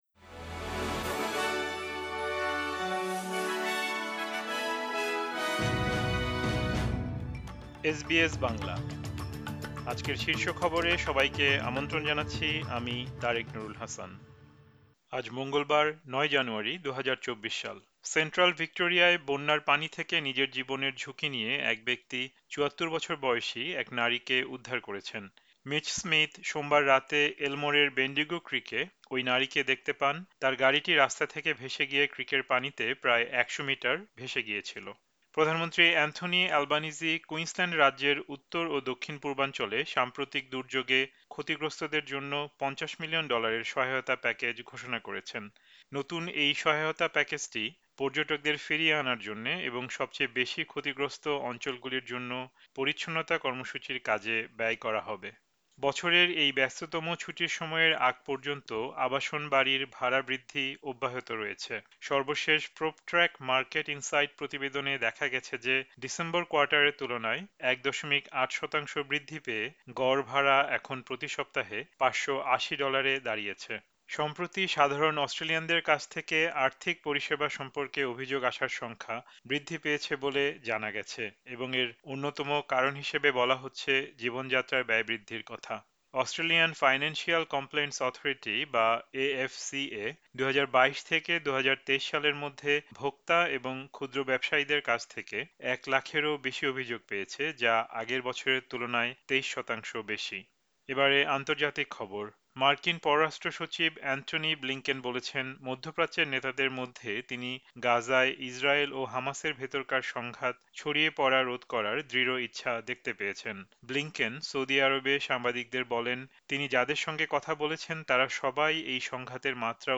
এসবিএস বাংলা শীর্ষ খবর: ৯ জানুয়ারি, ২০২৪